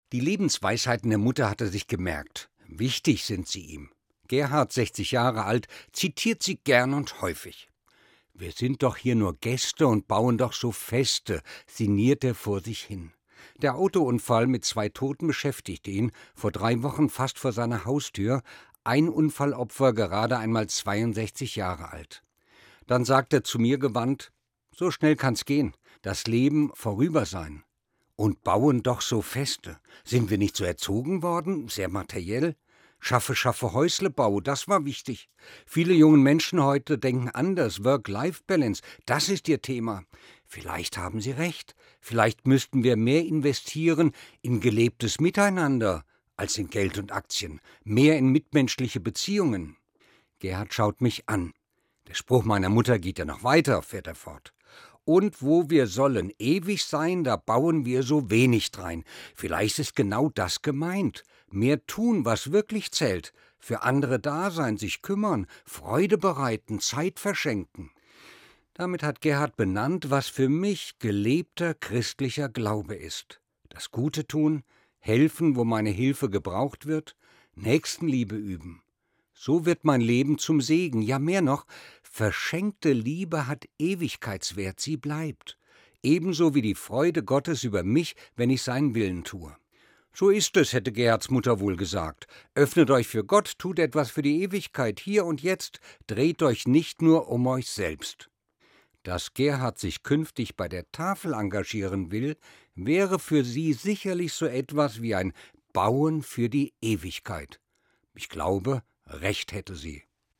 Evangelischer Pfarrer, Fulda